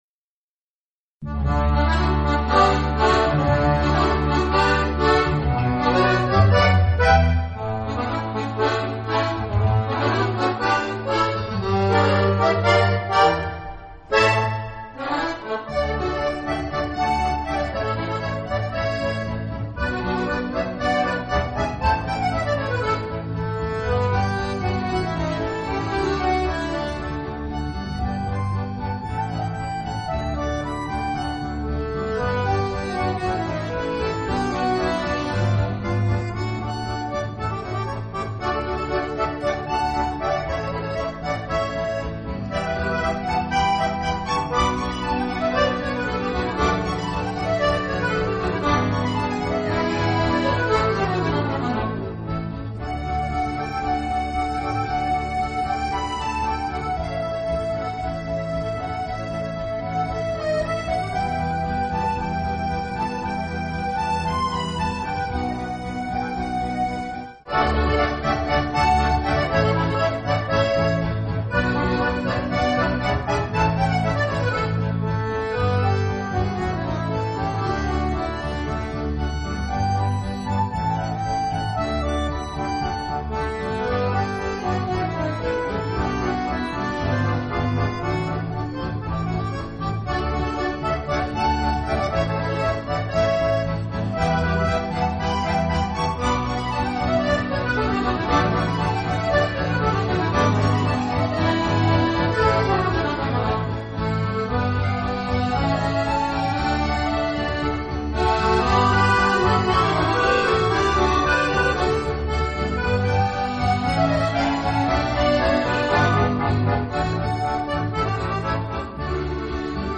2004 – Akkordeonorchester Neustadt bei Coburg e. V.